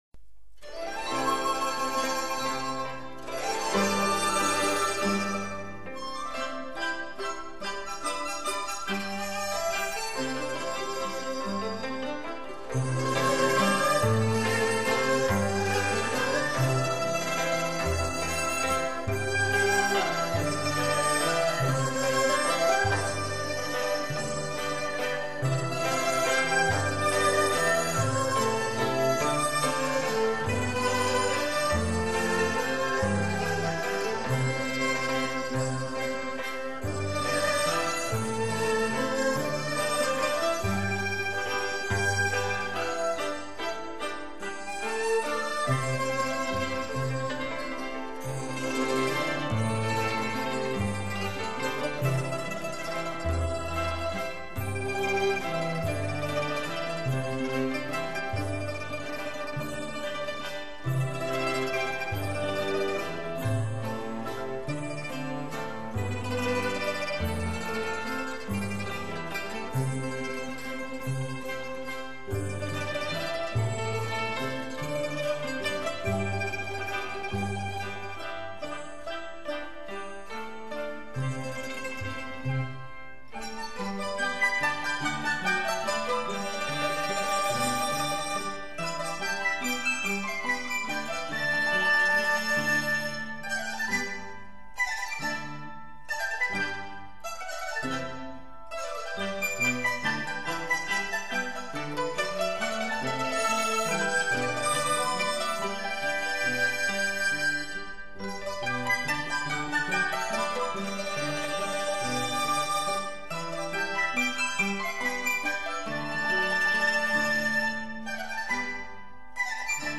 乐队演奏大气 乐器定位清晰 录音效果一流 有纵深感 堪称专业发烧典范
这是非常精彩的一张中国民乐唱片，所收作品大都喜庆、活泼、健康，从